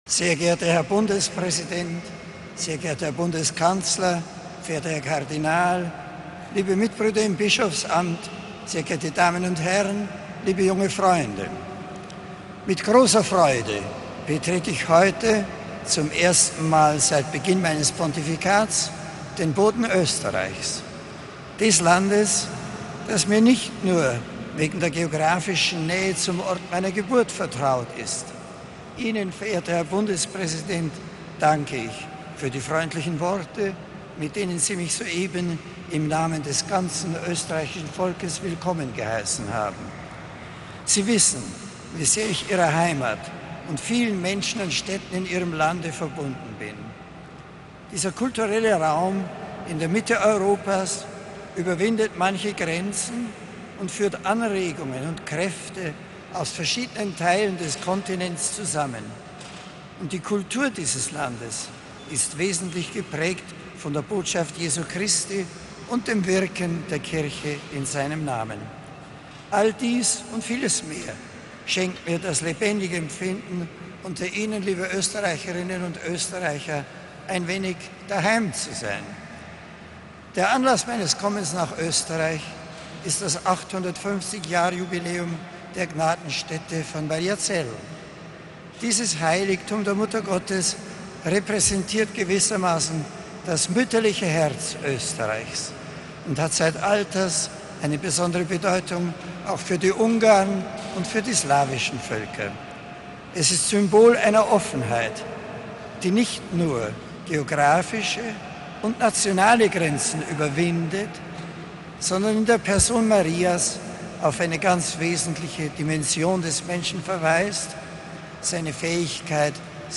Der Papst in Wien: Ansprache am Flughafen
MP3 Am Flughafen Wien-Schwechat hat Papst Benedikt XVI. seine erste Ansprache an die Österreicher gerichtet. Das Land sei ihm vertraut und habe - aufgrund der geographischen Lage in der Mitte Europas - eine wichtige Brückenfunktion.